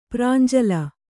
♪ prānjala